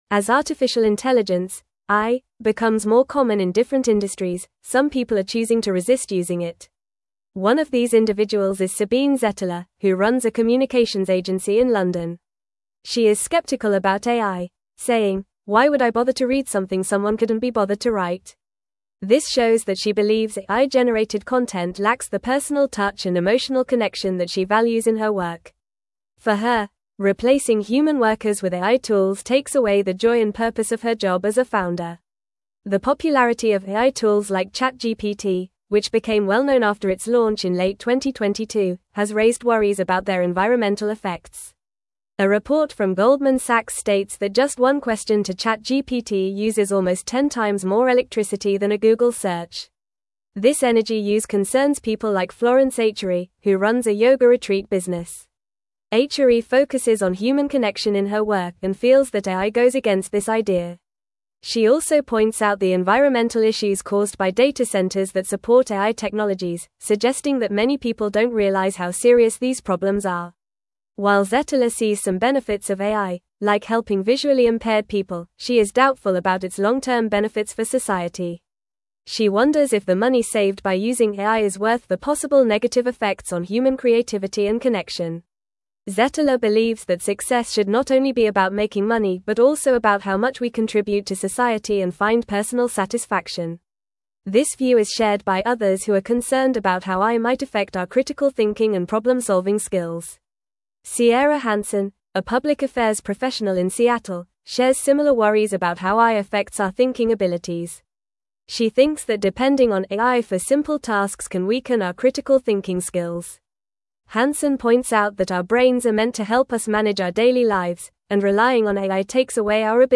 Fast
English-Newsroom-Upper-Intermediate-FAST-Reading-Resistance-and-Acceptance-of-AI-in-Creative-Industries.mp3